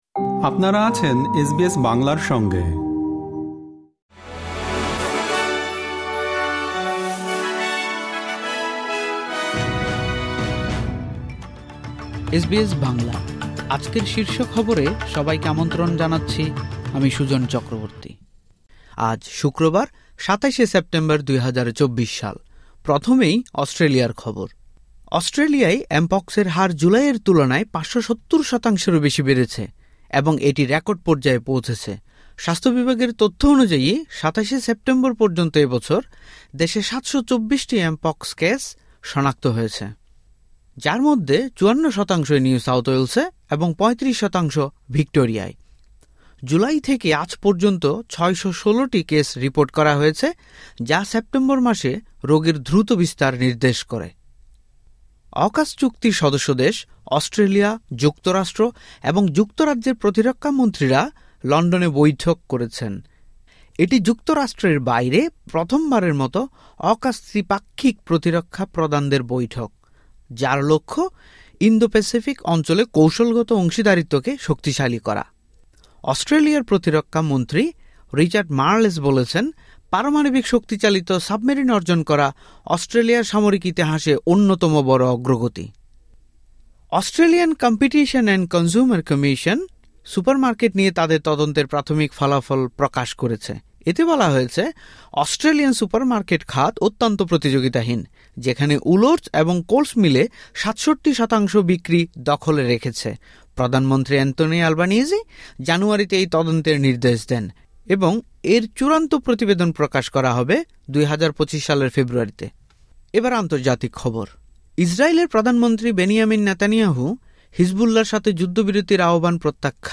এসবিএস বাংলা শীর্ষ খবর: ২৭ সেপ্টেম্বর, ২০২৪